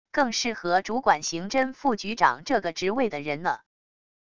更适合主管刑侦副局长这个职位的人呢wav音频生成系统WAV Audio Player